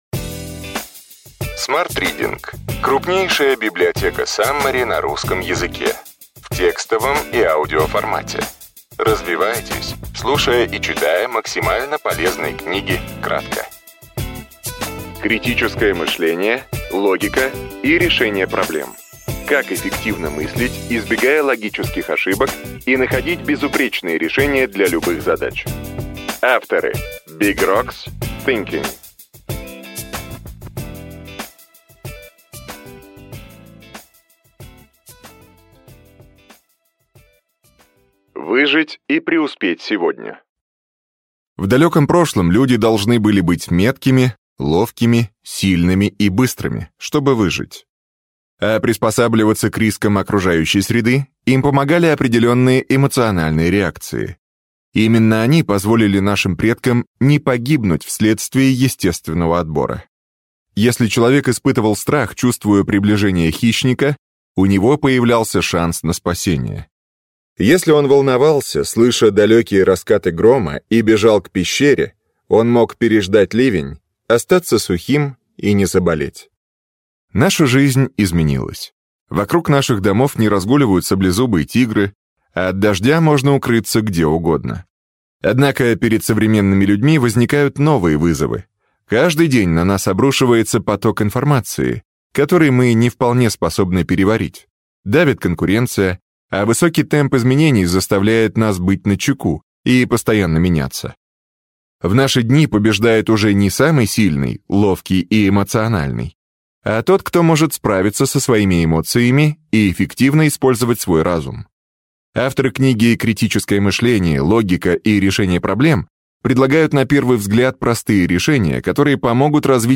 Аудиокнига Критическое мышление, логика и решение проблем.